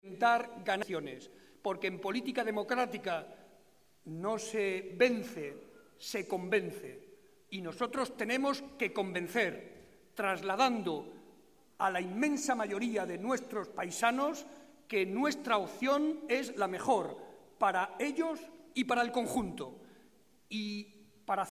Barreda que hacía estas declaraciones en el marco de la cena que con motivo de la Navidad celebra tradicionalmente el PSOE de Guadalajara, explicó además que el Grupo Parlamentario Socialista presentará una enmienda en la que se defenderán todos los planteamientos que reivindica nuestra Comunidad Autónoma, entre ellos el agua.
Audio Barreda cena navidad psoe gu 3